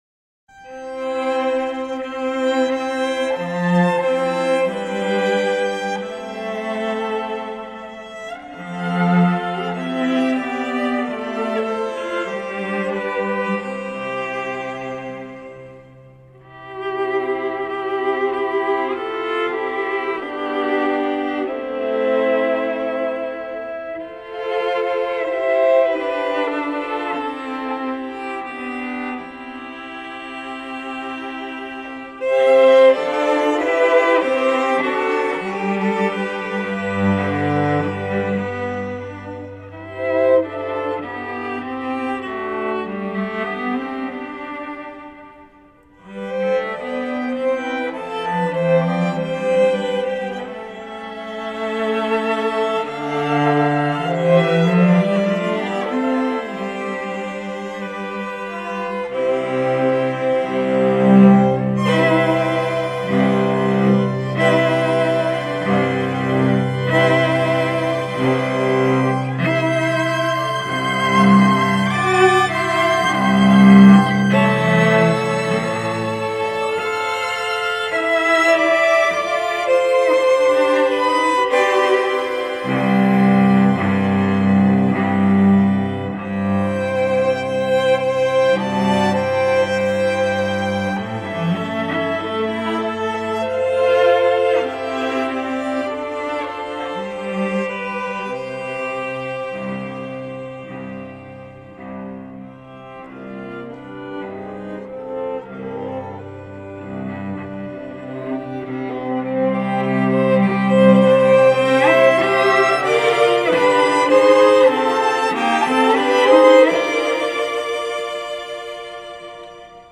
A deeply resonant setting for String Quartet
Type: String Quartet